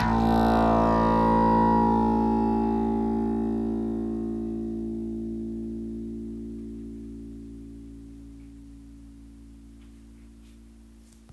tampura
C’est un instrument de quatre ou cinq cordes sans frettes qui a la particularité de posséder un chevalet dont la courbe permet le dégagement des harmoniques.
2-SON-DUNE-SEULE-CORDE.m4a